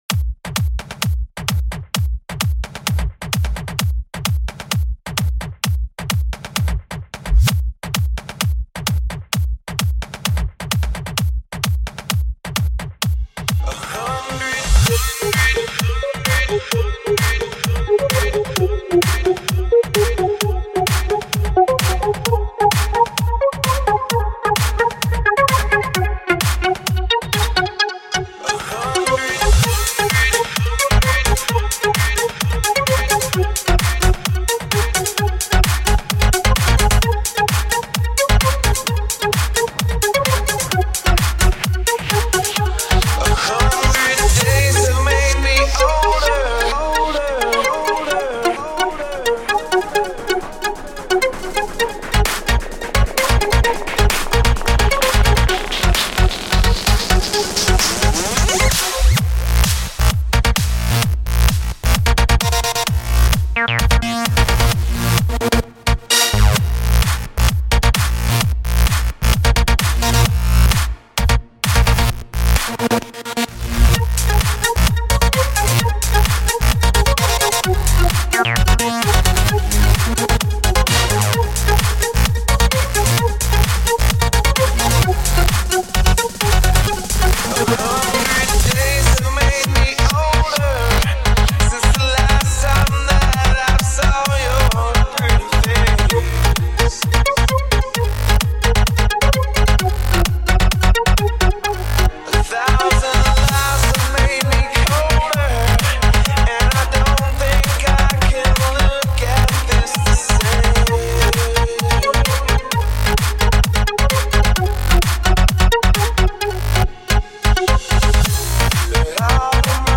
HomeMp3 Audio Songs > Others > Single Dj Mixes